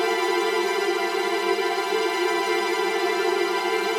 Index of /musicradar/gangster-sting-samples/Chord Loops
GS_TremString-G7.wav